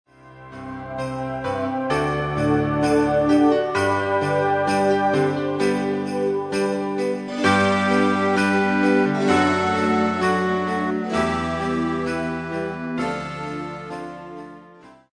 Sounds like christmas music.